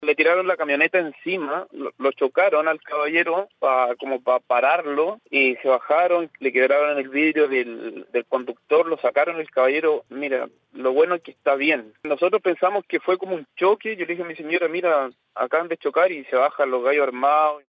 Fue durante la mañana del día lunes cuando auditores llamaron a la línea telefónica de Radio Bío Bío en Valparaíso para comentar e informar sobre un portonazo que ocurrió en el sector de Los Pinos en Reñaca.
cu-persecucion-camioneta-relato-testigo-.mp3